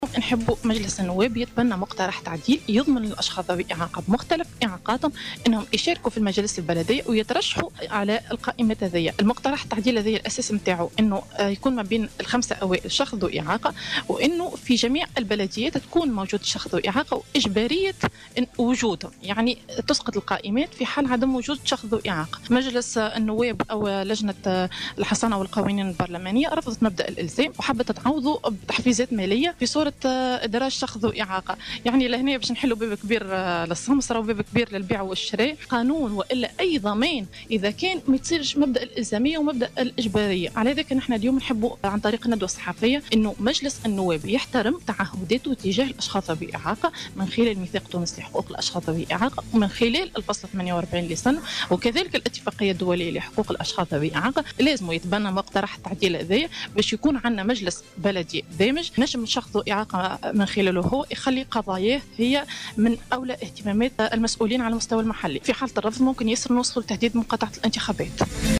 وأضافت خلال ندوة صحفية عقدتها الجمعية اليوم الثلاثاء بالتعاون مع المنظمة التونسية للدفاع عن الأشخاص ذوي الإعاقة بعنوان " وجود الأشخاص ذوي الإعاقة في المجالس البلدية حق مش مزية" أن لجنة الحصانة والقوانين البرلمانية رفضت مبدأ الإلزام و أرادت تعويضه بتحفيزات مالية في صورة إدراج شخص ذي إعاقة وهو ما سيفتح الباب أمام "السمسرة "والبيع والشراء"، وفق تعبيرها.